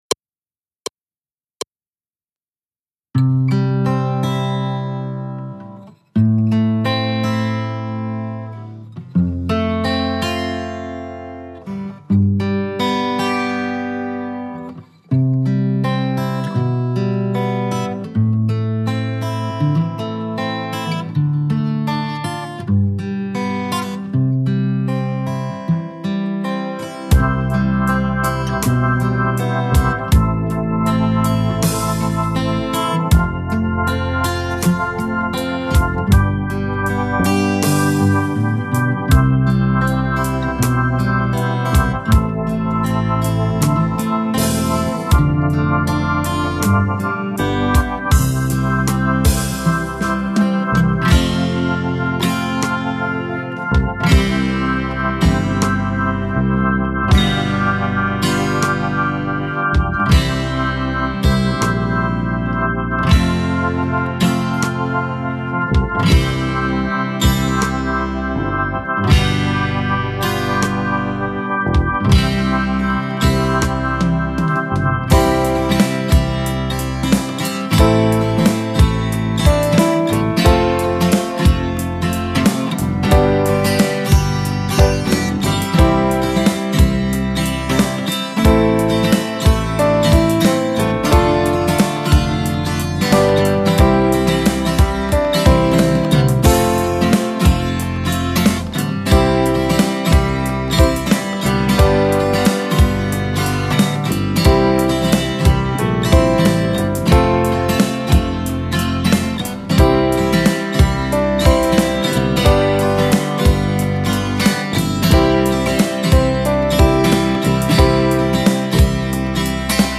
base sense melodia